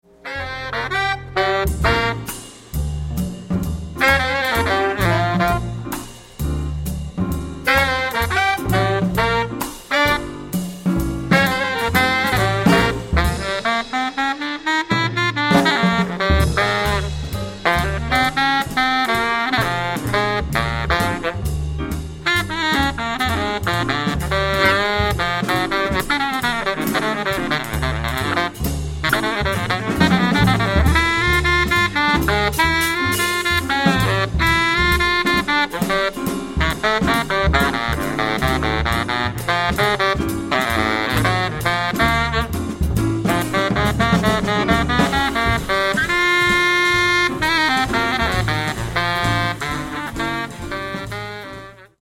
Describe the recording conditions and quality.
Recorded live at the Y Theatre Leicester November 2007